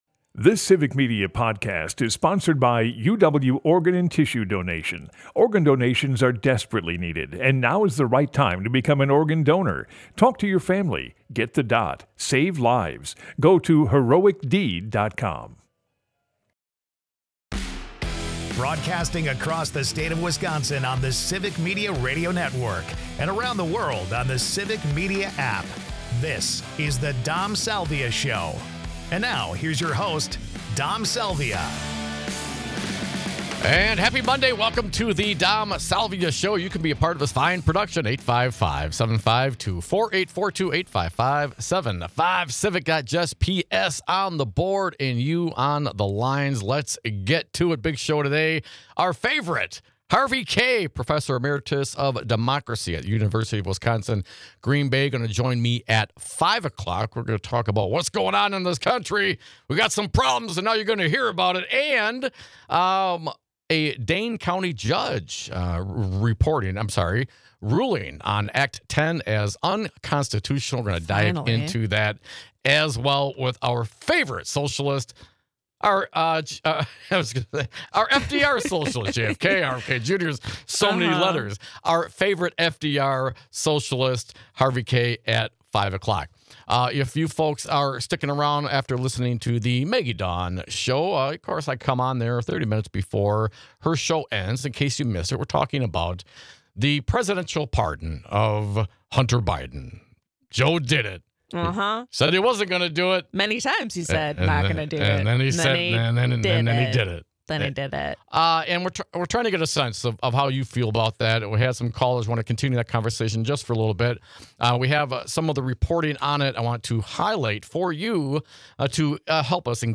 So do our callers, so we process it together.